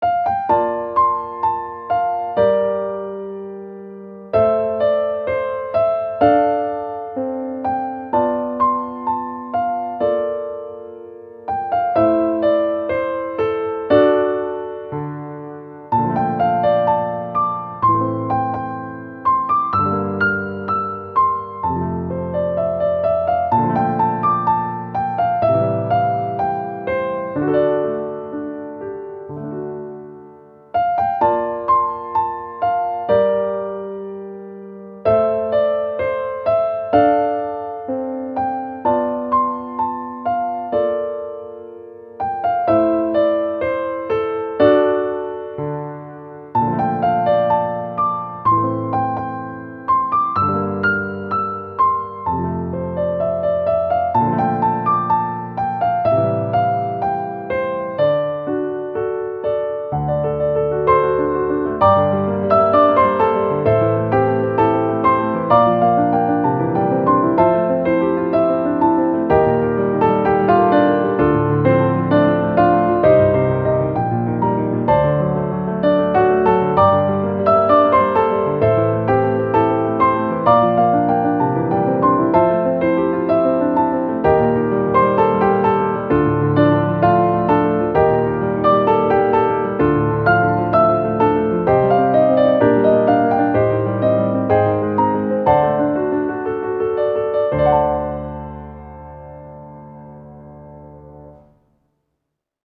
ogg(R) - 寂しさ 郷愁 情熱的